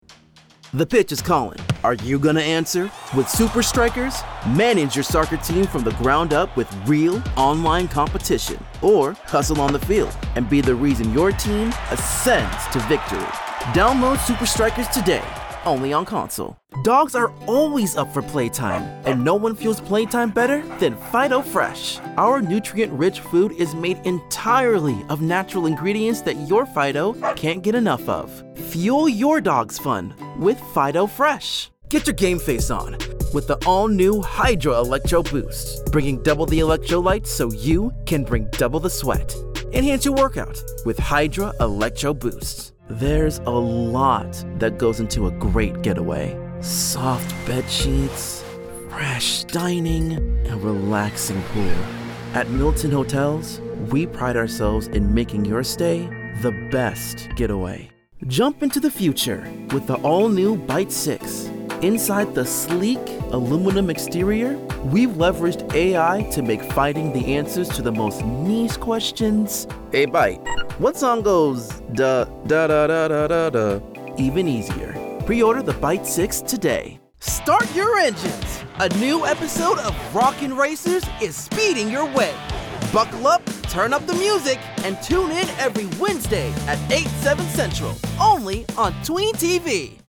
Teenager, Young Adult, Adult
COMMERCIAL 💸
sincere
smooth/sophisticated
upbeat
warm/friendly